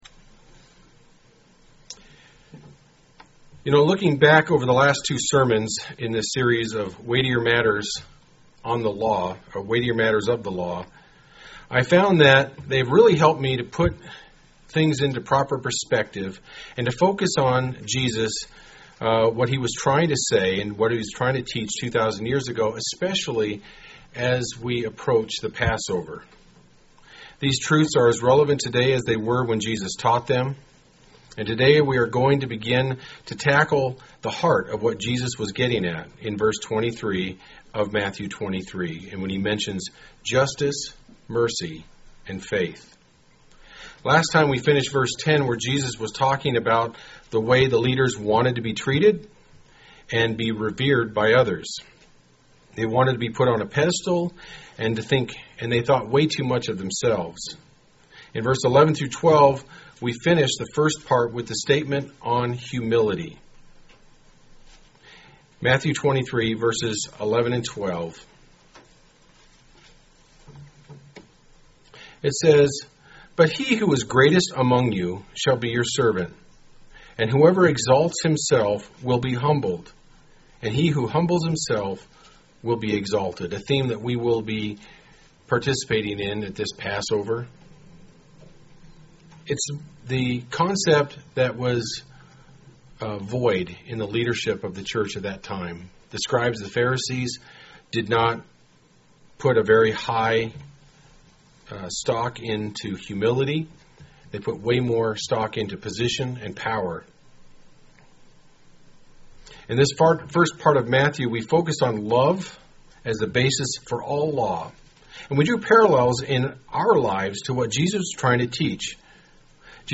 UCG Sermon Notes Weightier Matters Part 3